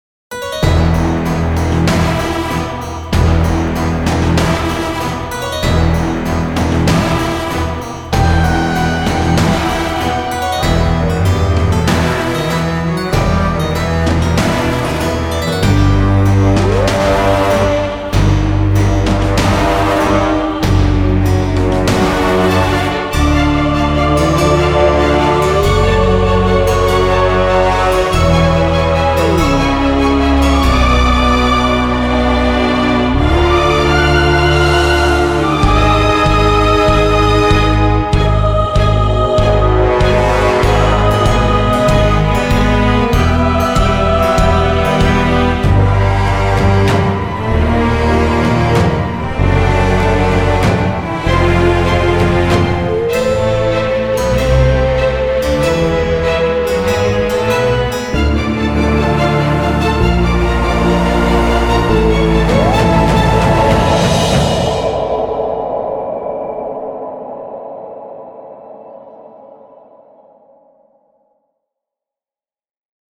главная тема опенинга